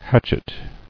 [hatch·et]